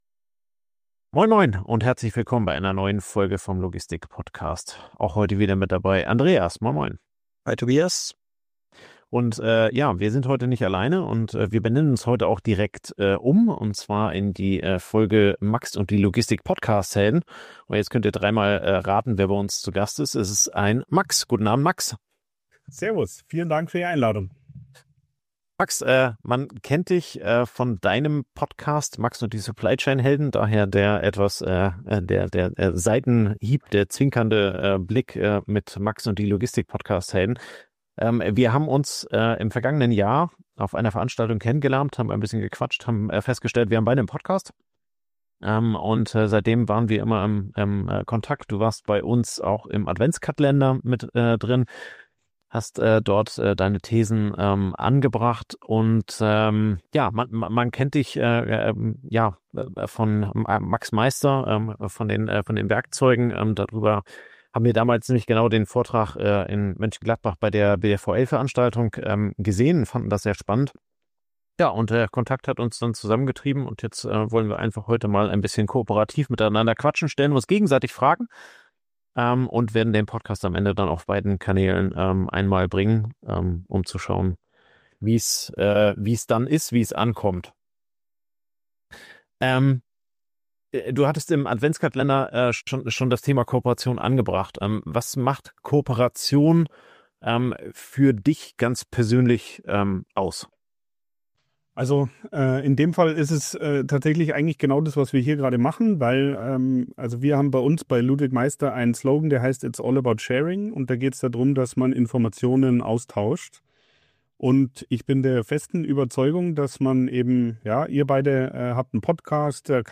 Im Mittelpunkt steht die Frage, was man aus jahrelanger Podcast-Erfahrung für Führung, Zusammenarbeit und Veränderung in Unternehmen lernen kann. Dabei geht es weniger um Technik oder Systeme, sondern vor allem um Menschen, Haltung und Mindset. Eine offene, ehrliche Diskussion über Lernen, Dranbleiben und den echten Mehrwert von Kooperation.